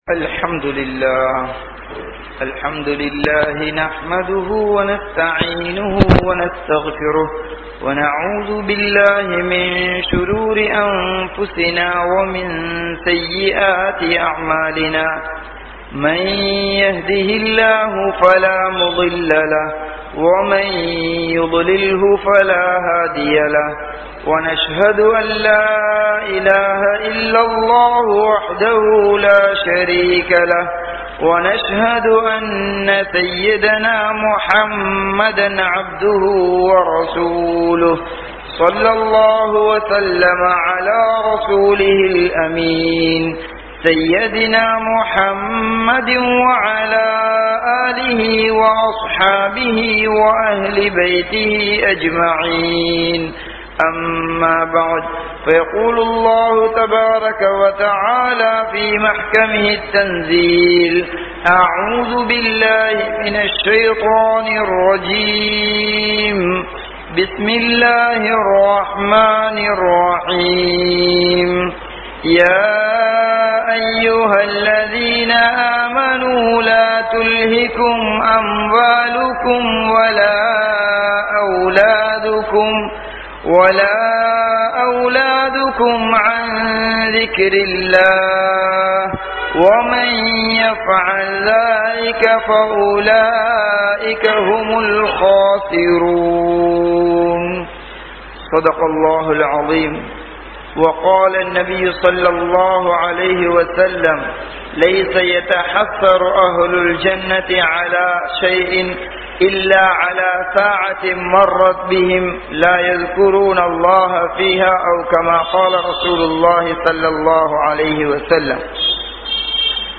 Marumai Vaalkai (மறுமை வாழ்க்கை) | Audio Bayans | All Ceylon Muslim Youth Community | Addalaichenai